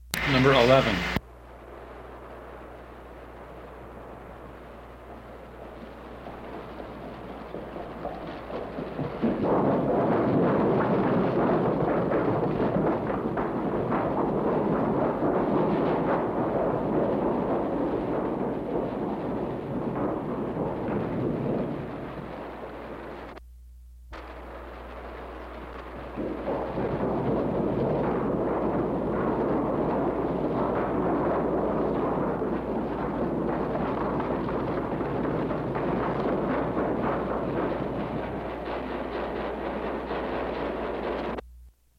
古老的马车 " G5211盖桥上的越野车
描述：马和马车在金属被遮盖的桥。闷闷不乐，低沉的隆隆声。
我已将它们数字化以便保存，但它们尚未恢复并且有一些噪音。